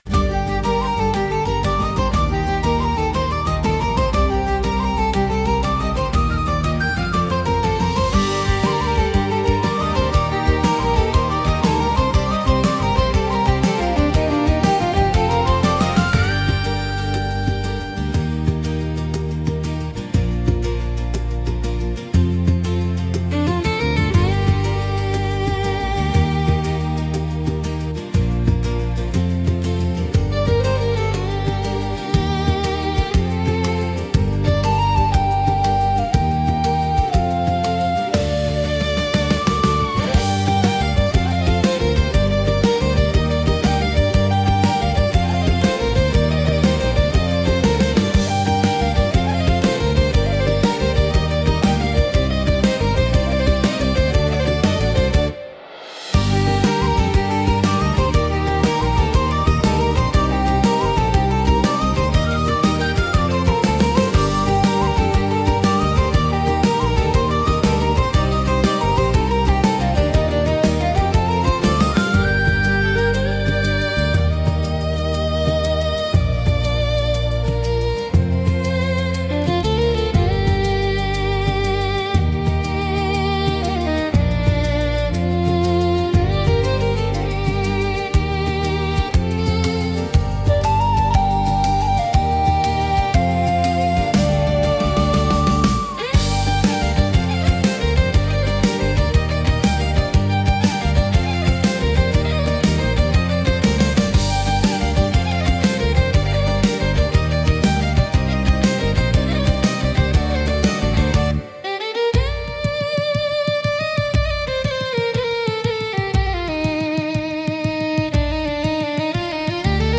ケルト音楽【作業用BGM】～民族～
SunoAIにて作成